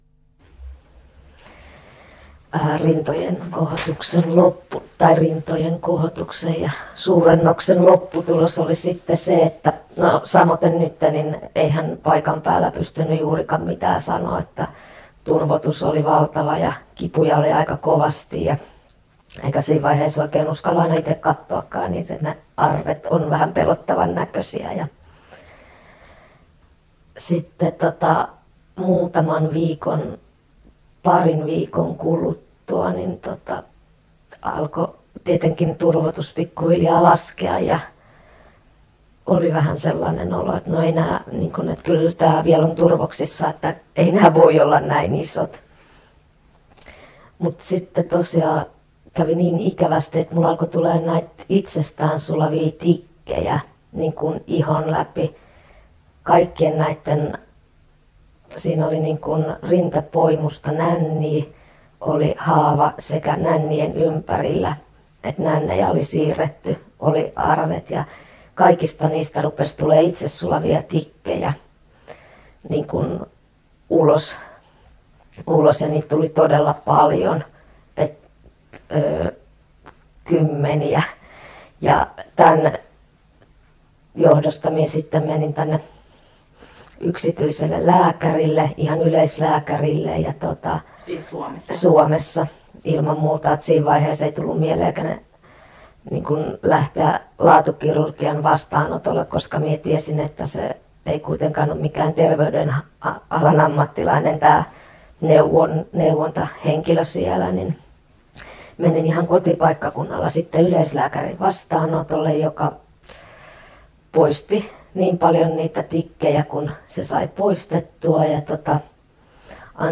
Ääni muutettu.